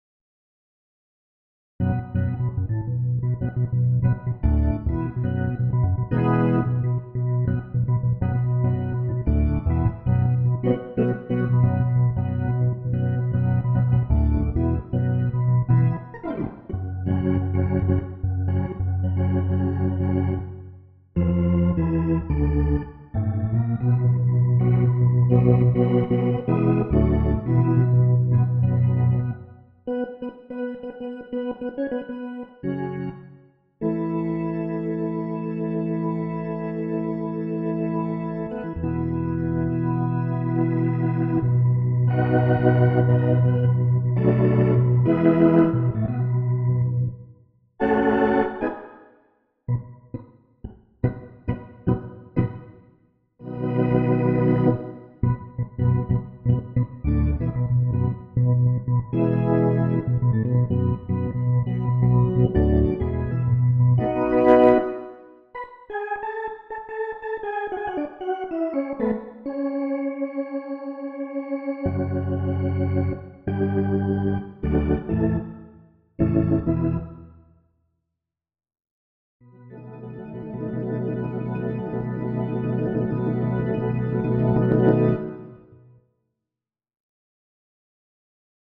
stereo
I play a bit like a blues
strong blues organ